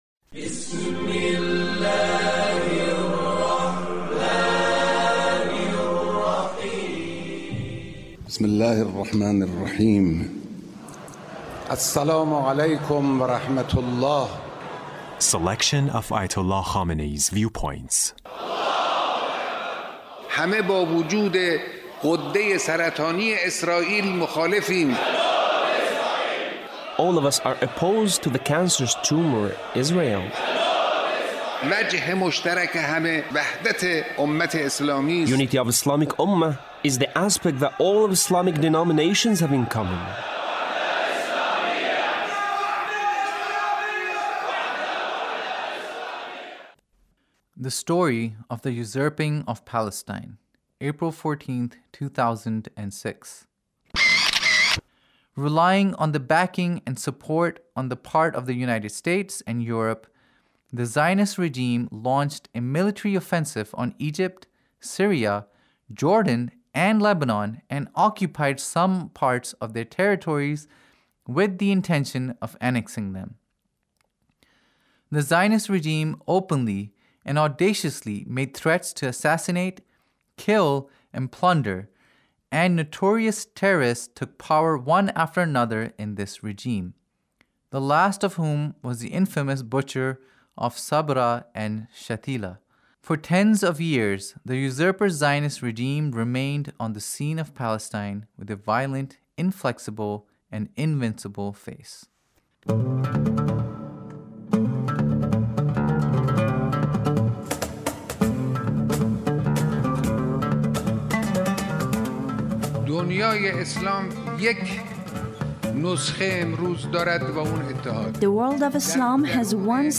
Leader's Speech (1883)
Leader's Speech about Palestine